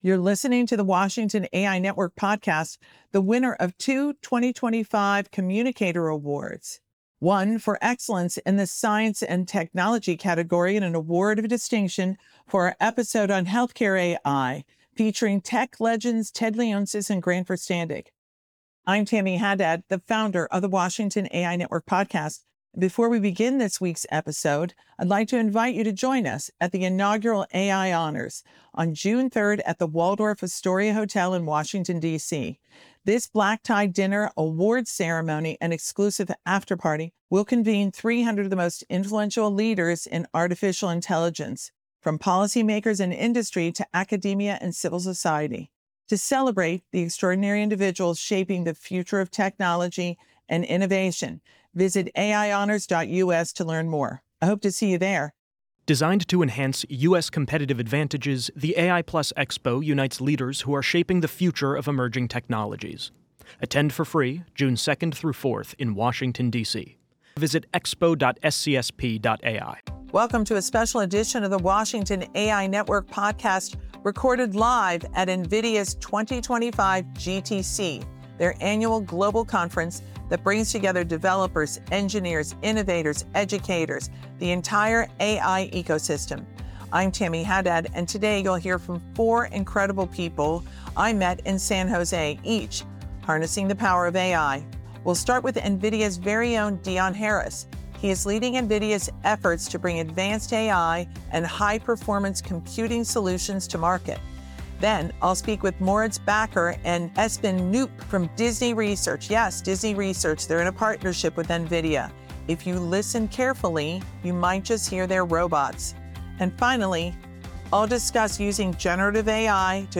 Behind-the-scenes interviews from NVIDIA’s GTC 2025, from AI factories to Disney Animation’s emotionally intelligent robots.